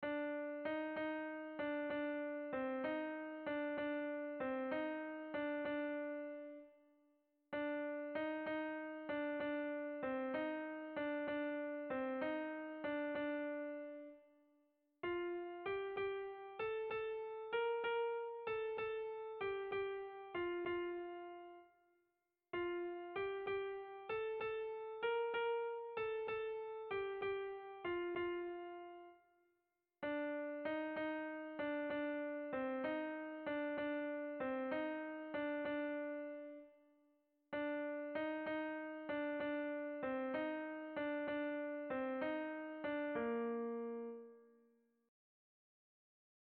Air de bertsos - Voir fiche   Pour savoir plus sur cette section
Hamabiko txikia (hg) / Sei puntuko txikia (ip)
AB